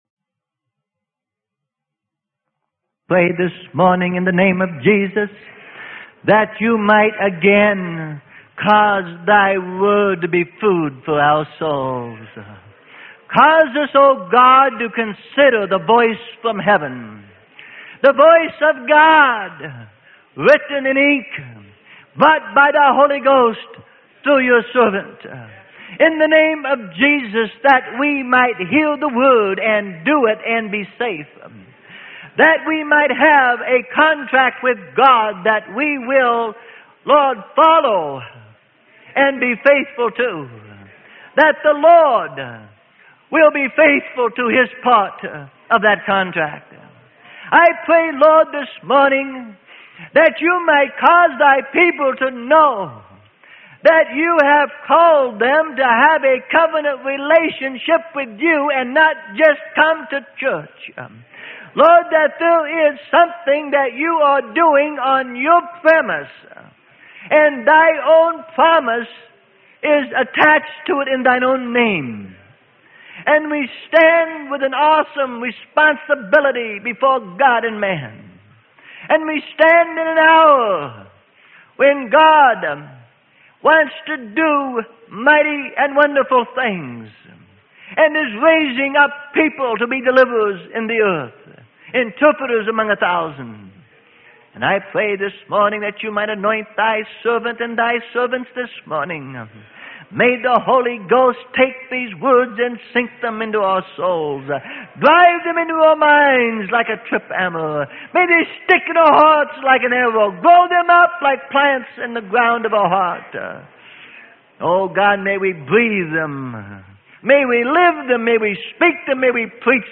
Sermon: We Are Under Contract - Part 2 - Freely Given Online Library